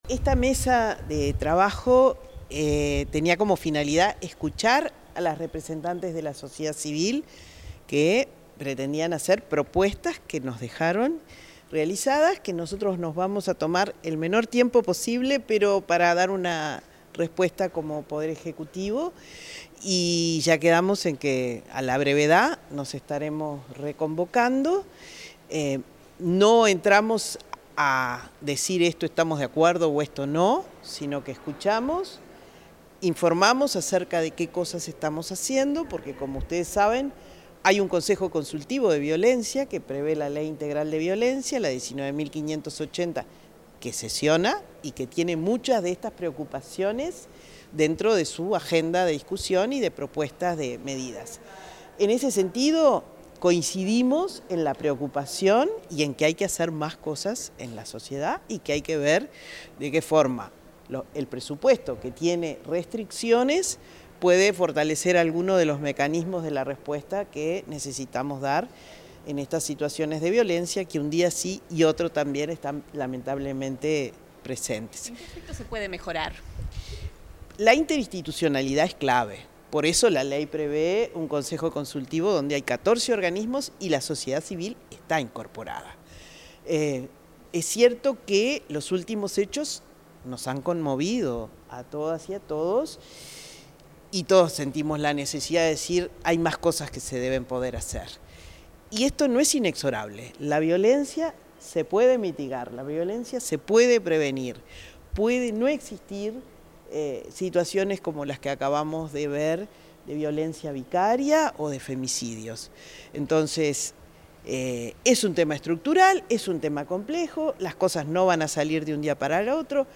Declaraciones de la directora de Inmujeres, Mónica Xavier
La directora del Instituto Nacional de las Mujeres (Inmujeres), Mónica Xavier, efectuó declaraciones a la prensa, luego de participar en una mesa de